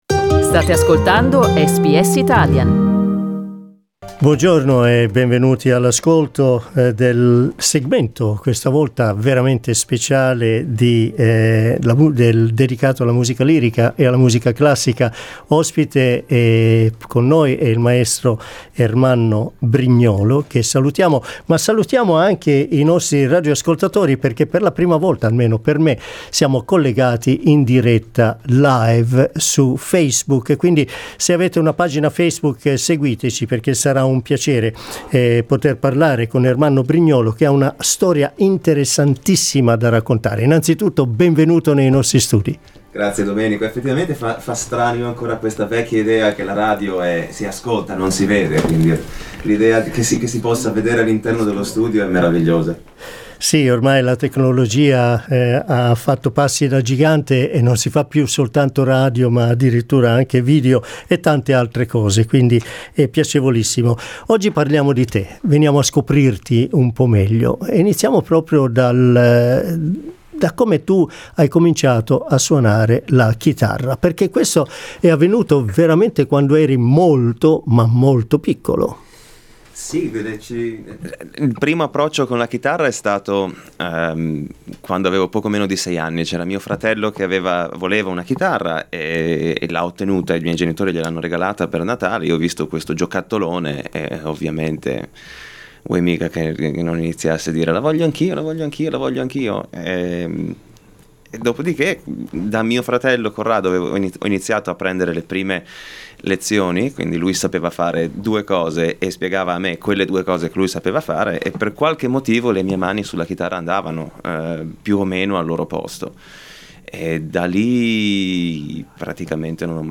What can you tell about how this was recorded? "live" in our studios performed live